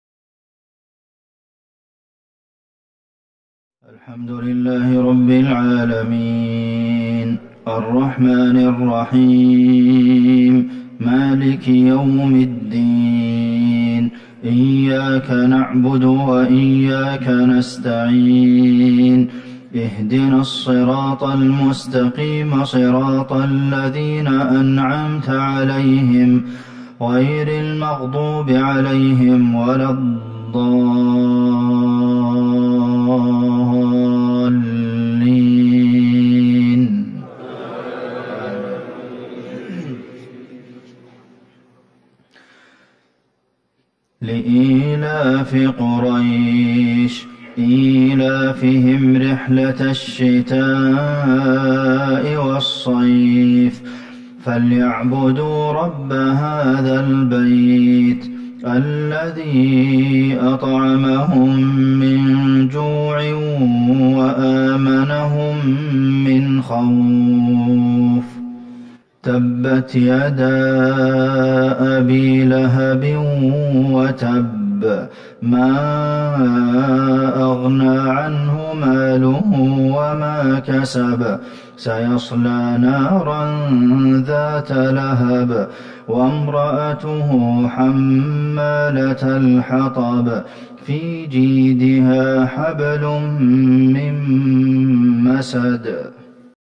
صلاة المغرب ١٦ جمادي الاولى ١٤٤١هـ سورة قريش والمسد Maghrib prayer 4-1-2020 from Surat Quraish and Al-Masd > 1441 🕌 > الفروض - تلاوات الحرمين